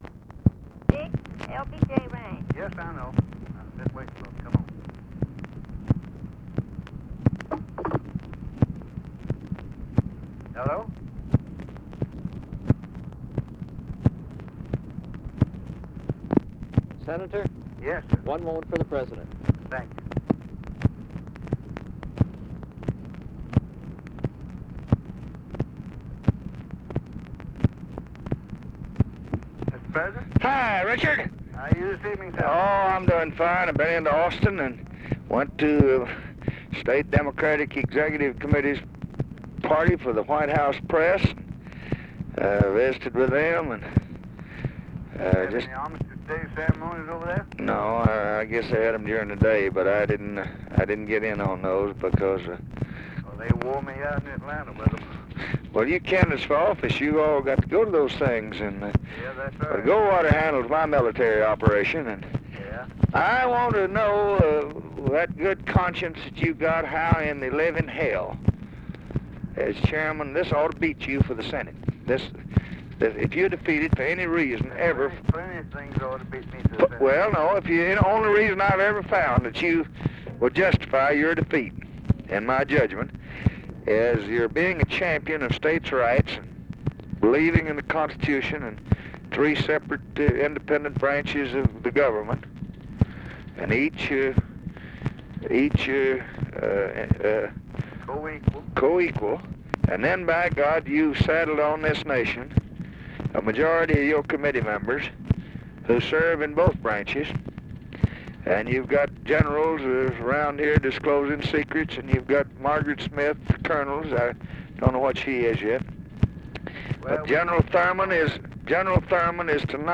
Conversation with RICHARD RUSSELL and JACK VALENTI, November 12, 1964
Secret White House Tapes